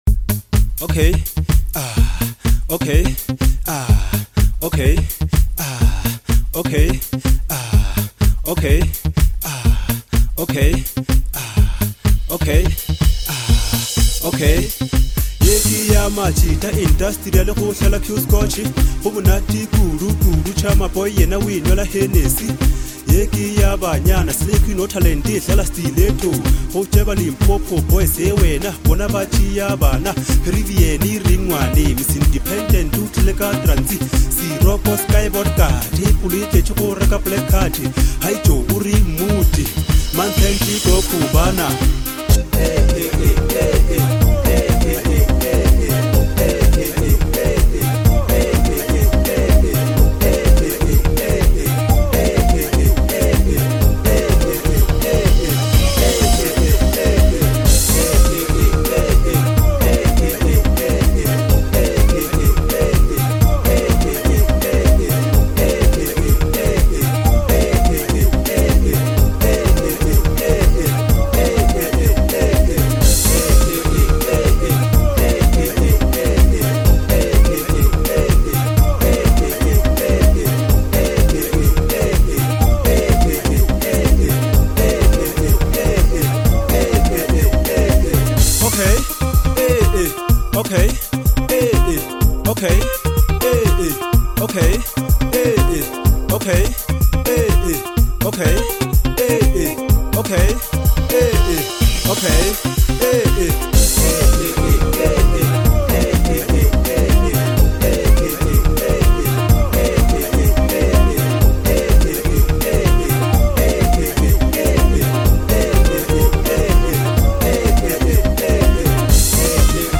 a radio and club-friendly Jam